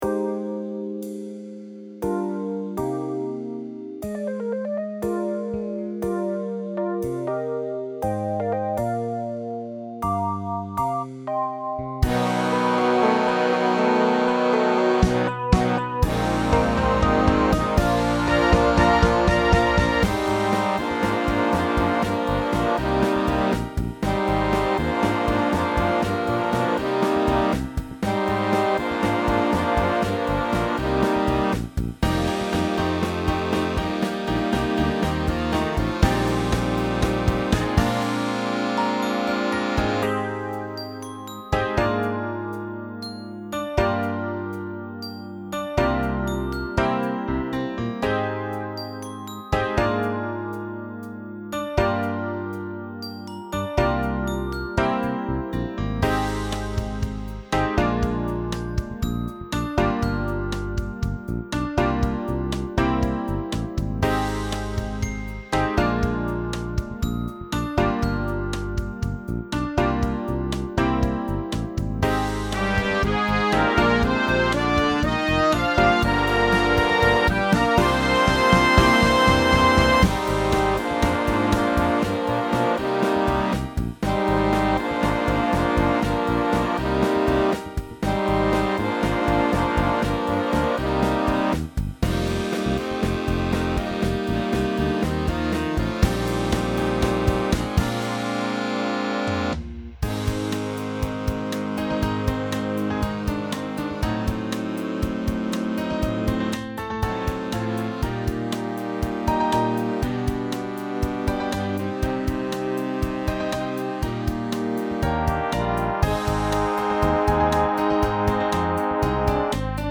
Motown classic.
Scored for full big band.